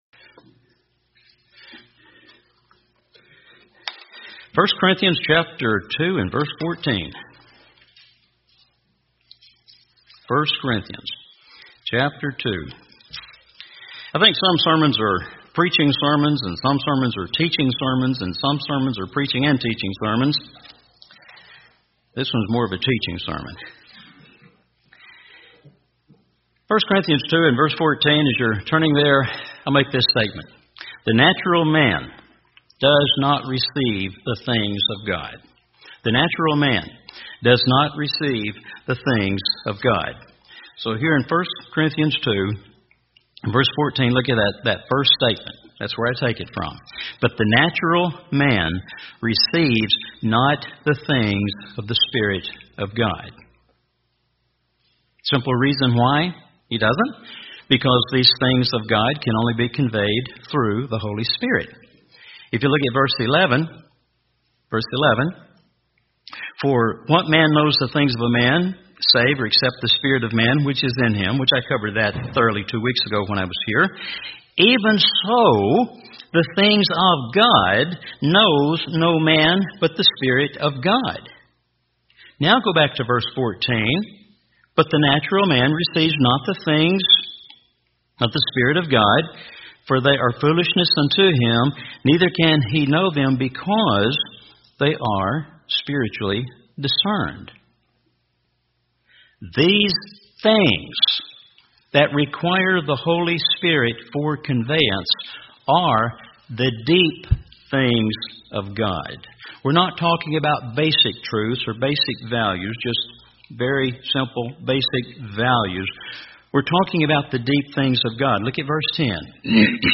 This one is more of a teaching sermon. 1 Corinthians 2 and verse 14, as you're turning there, I'll make this statement.